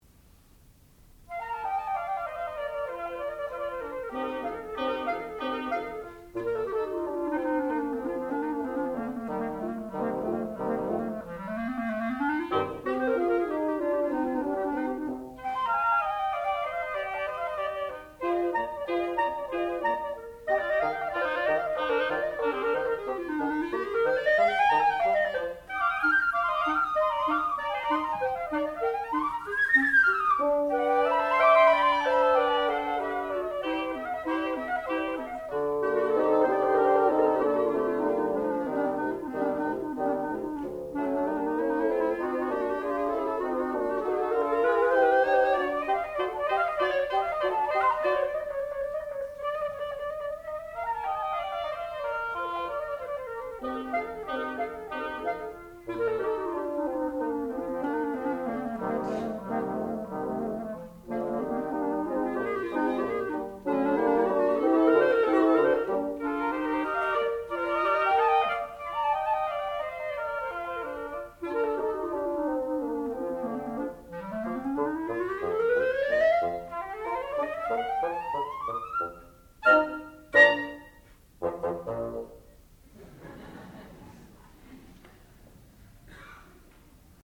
sound recording-musical
classical music
oboe
clarinet
flute